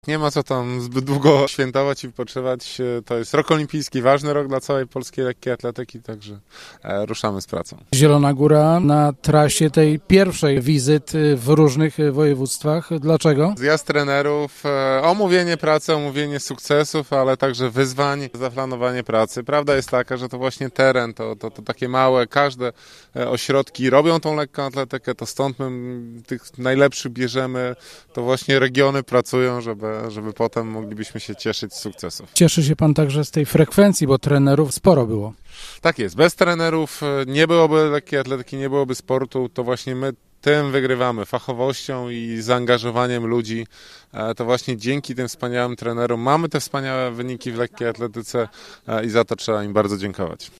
– Ruszamy z pracą, bo to ważny olimpijski rok – powiedział Tomasz Majewski: